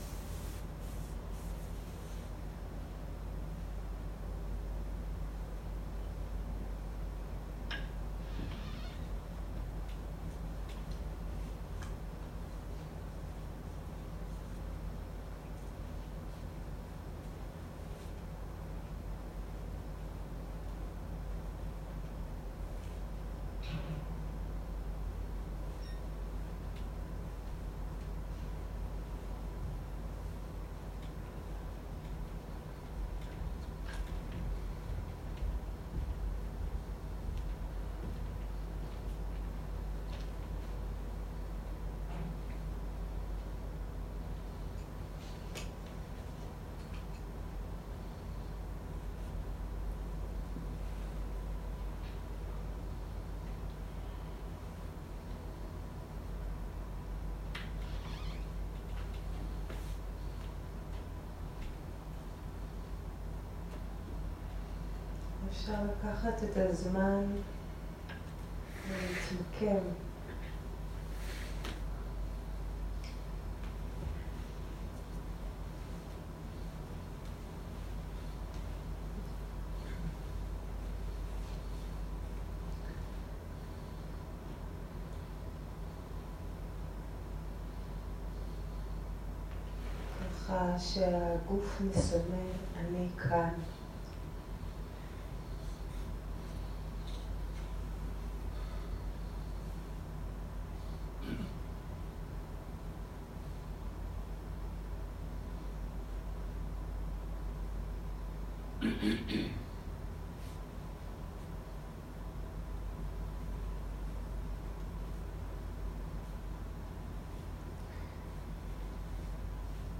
מדיטציה מונחית
Dharma type: Guided meditation שפת ההקלטה